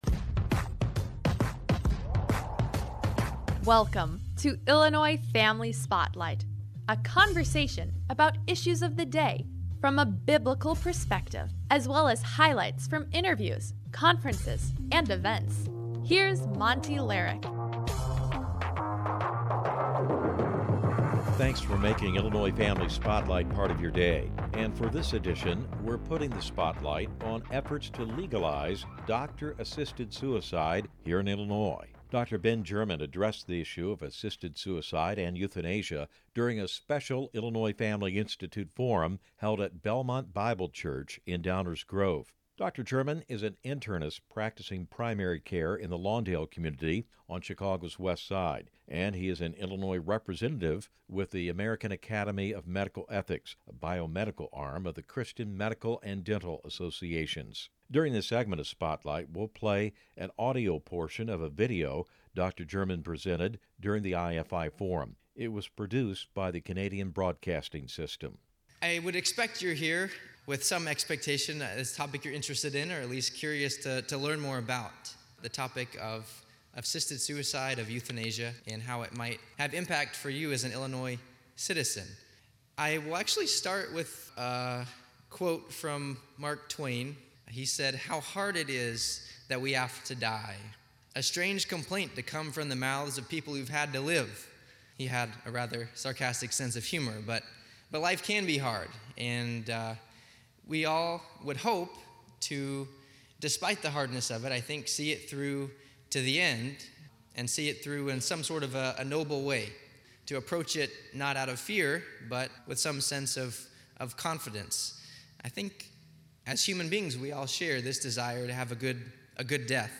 During a special forum hosted by the Illinois Family Institute at Belmont Bible Church in Downers Grove